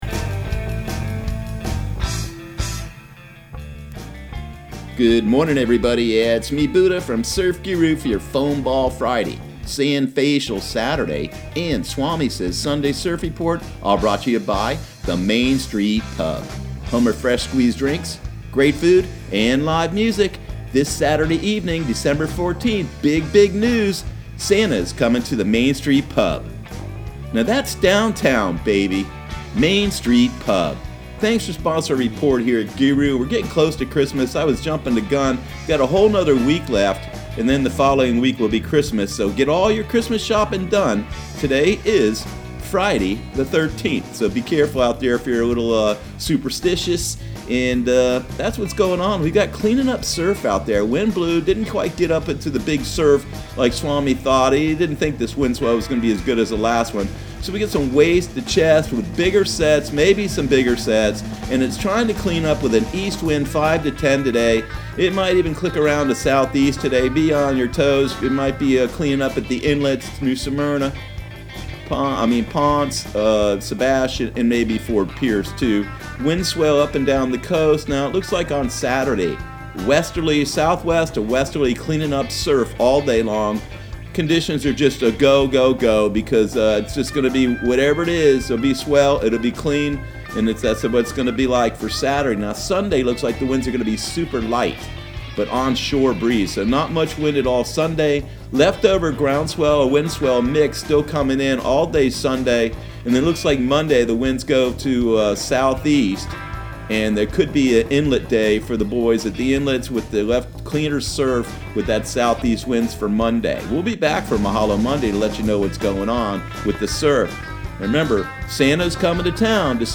Surf Guru Surf Report and Forecast 12/13/2019 Audio surf report and surf forecast on December 13 for Central Florida and the Southeast.